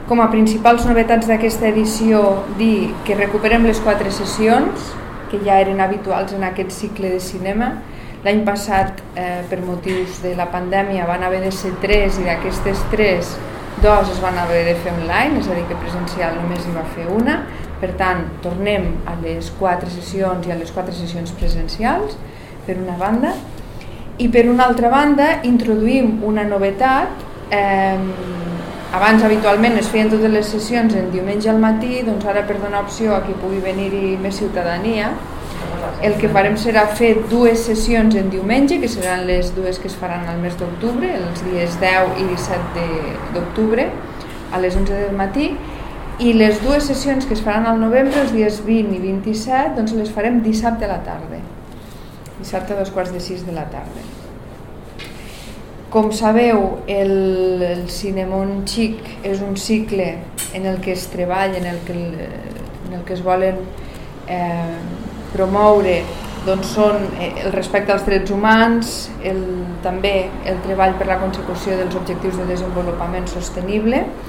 tall-de-veu-de-la-tinent-dalcalde-sandra-castro-sobre-cinemon-xic-cinema-infantil-en-valors-2021